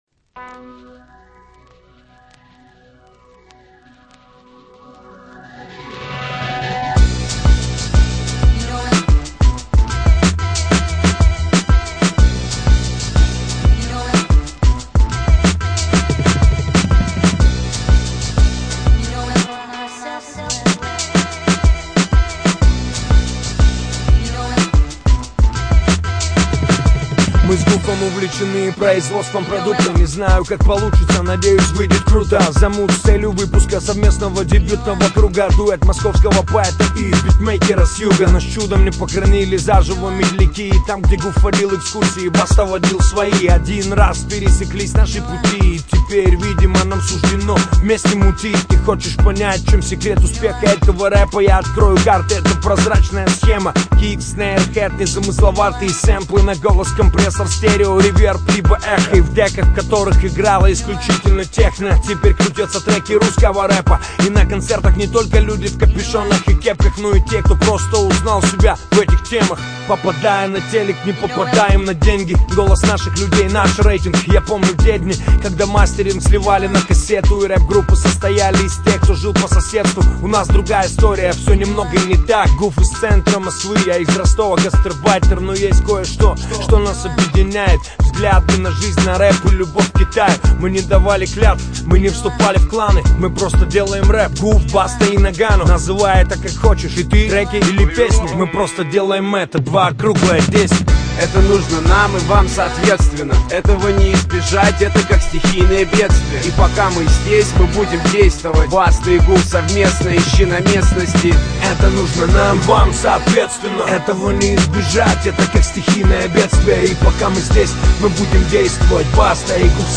rap музыка